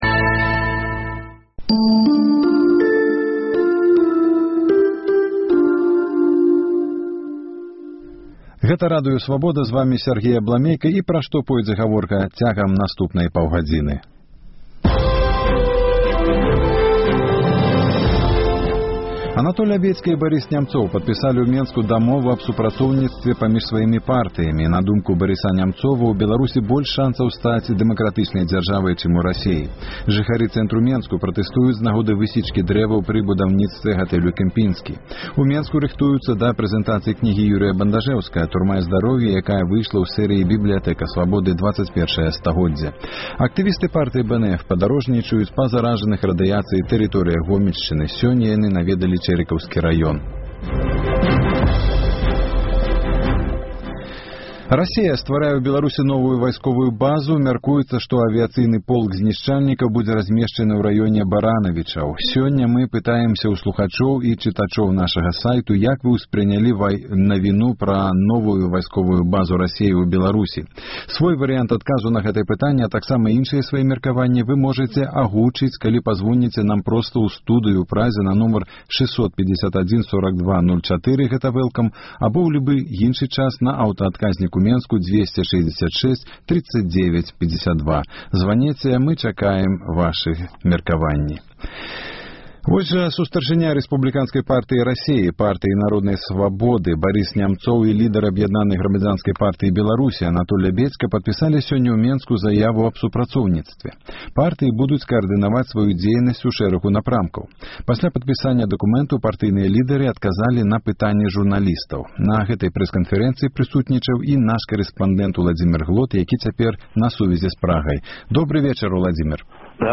Госьць эфіру — старшыня АГП Анатоль Лябедзька. Расея стварае ў Беларусі новую вайсковую базу.